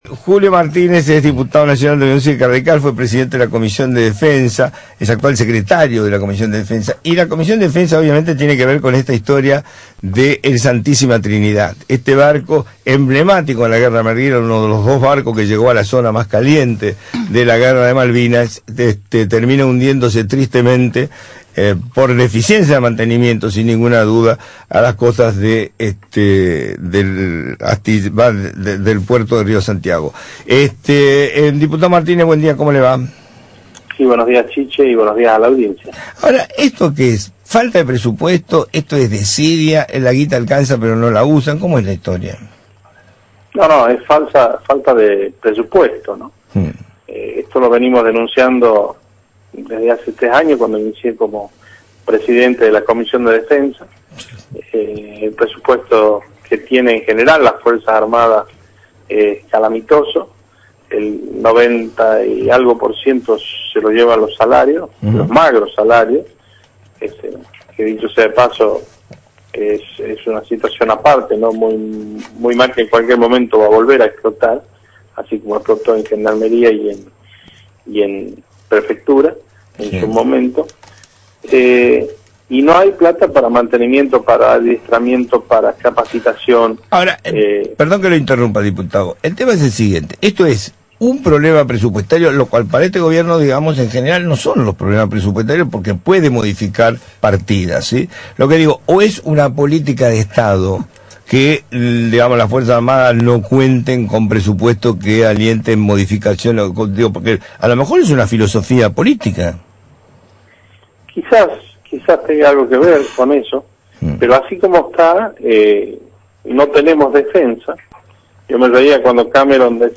Lo dijo el diputado radical Julio Martínez, secretario de la Comisión de Defensa, en «Hola Chiche» por Radio 10.
Julio Martínez, diputado nacional, por Radio 10